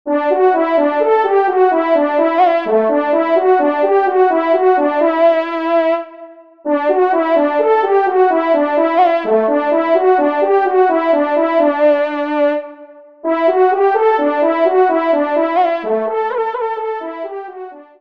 Solo Trompe      (Ton de vénerie)